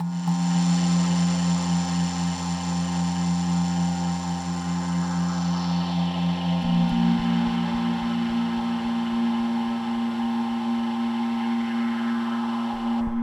synth03.wav